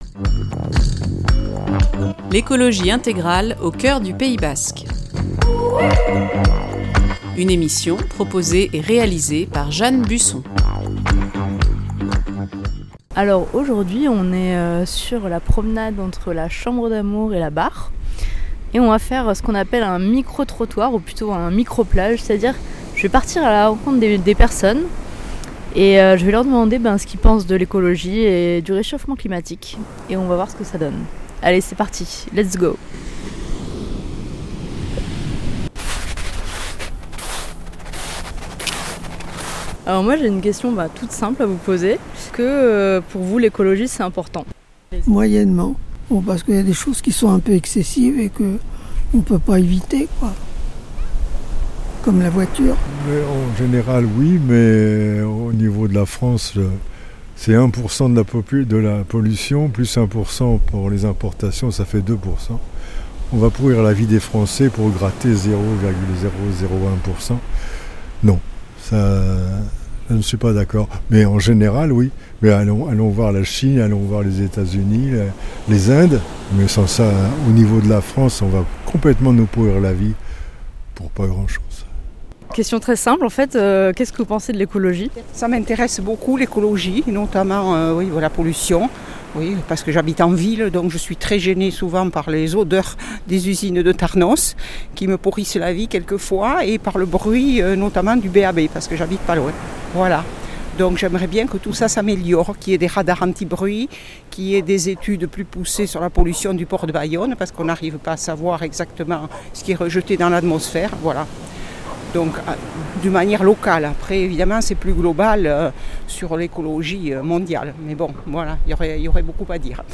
Est-ce que l’écologie et le changement climatique ça vous intéresse ? C’est ce que j’ai cherché à savoir en recueillant quelques avis, opinions de passants le long de la balade de la chambre d’Amour à la Barre à Anglet.